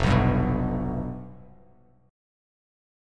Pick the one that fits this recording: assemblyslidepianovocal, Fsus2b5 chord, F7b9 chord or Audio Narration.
Fsus2b5 chord